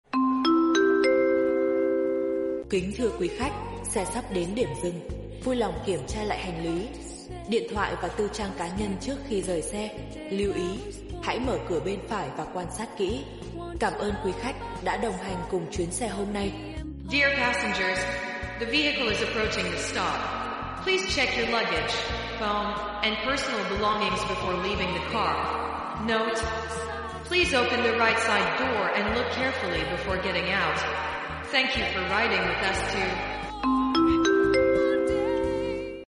Nhạc Xuống xe, Lời chào Tạm biệt cho xe chạy dịch vụ
Thể loại: Tiếng chuông, còi
Description: Nhạc xuống xe, Lời chào tạm biệt, Thông báo rời xe, Nhạc kết thúc chuyến đi, Lời nhắc xuống xe an toàn... đây là đoạn âm thanh dùng cho xe dịch vụ như taxi, xe công nghệ, xe du lịch.